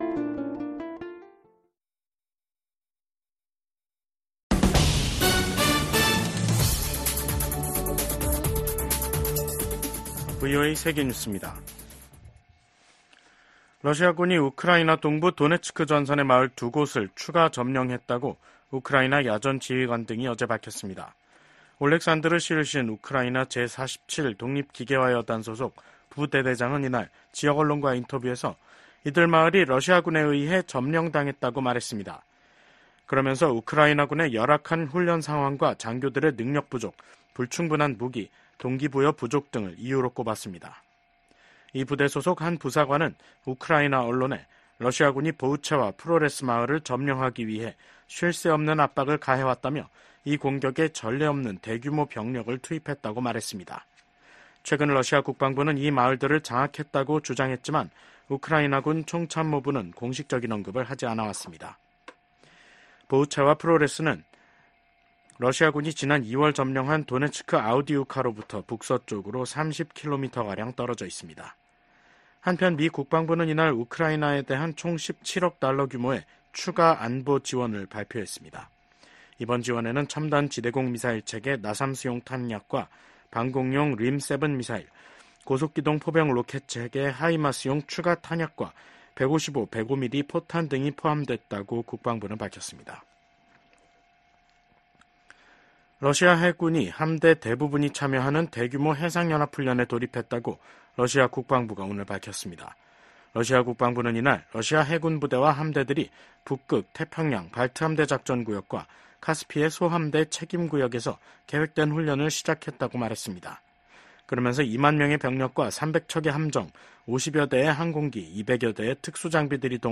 VOA 한국어 간판 뉴스 프로그램 '뉴스 투데이', 2024년 7월 30일 2부 방송입니다. 미국이 핵확산금지조약(NPT) 평가 준비회의에서 북한의 완전한 비핵화가 목표라는 점을 재확인했습니다. 미국과 일본, 인도, 호주 4개국 안보협의체 쿼드(Quad) 외무장관들이 북한의 탄도미사일 발사와 핵개발을 규탄했습니다. 북한 주재 중국대사가 북한의 ‘전승절’ 기념 행사에 불참하면서, 북한과 중국 양국 관계에 이상 징후가 한층 뚜렷해지고 있습니다.